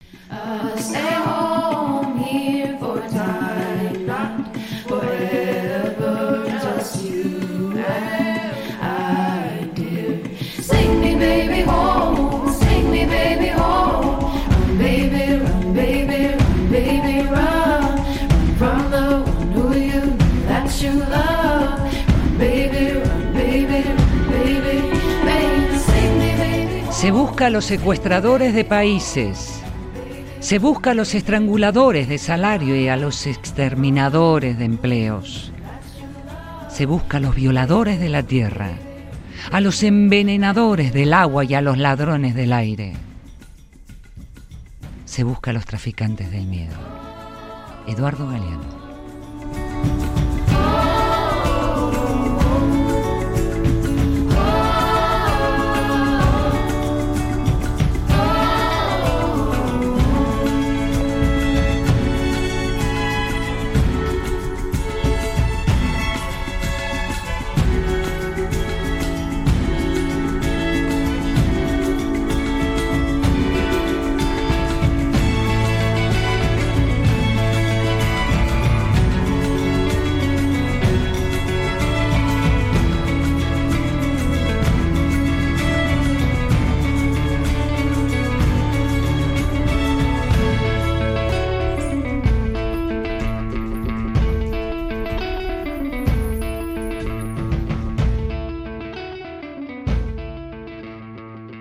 Traficantesel miedo, de Eduardo Galeano, con la voz de
durante el programa de fin de semana "La Fiaca", de Radio Vitoria, grupo EITB